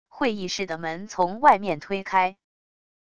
会议室的门从外面推开wav音频